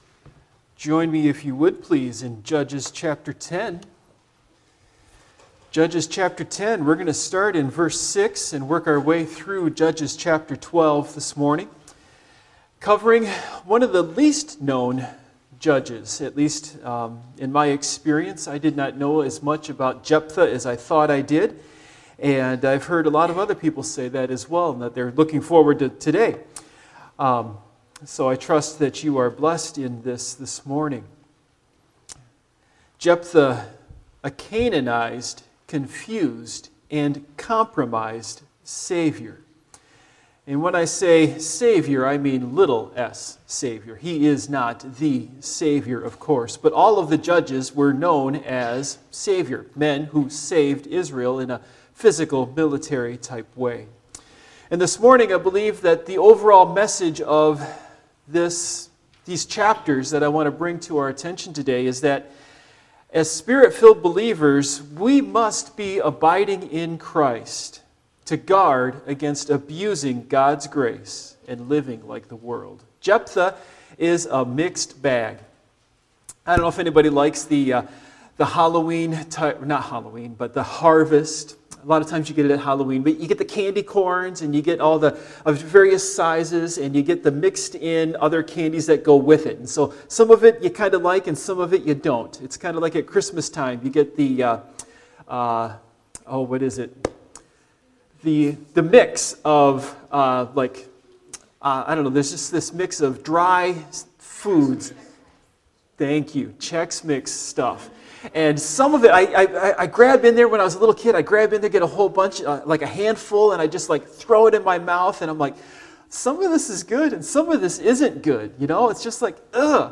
Passage: Judges 10:6-12:15 Service Type: Morning Worship